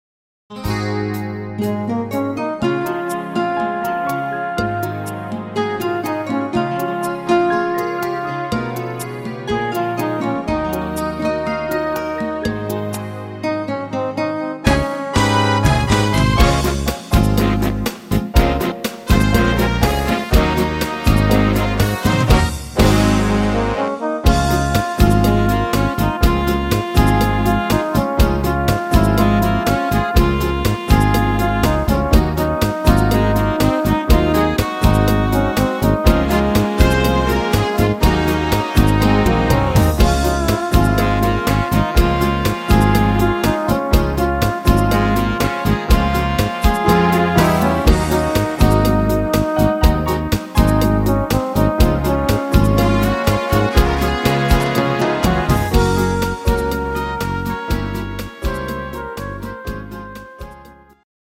Rhythmus  Cha cha
Art  Englisch, Jazz, Standard, Traditionell